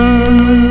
Index of /90_sSampleCDs/USB Soundscan vol.02 - Underground Hip Hop [AKAI] 1CD/Partition D/06-MISC
ORGAN 1   -R.wav